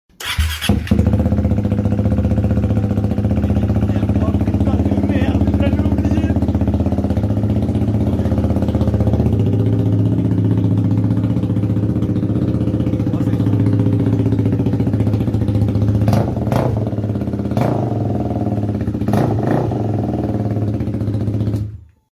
Start KTM Duke 390 exhaust sound effects free download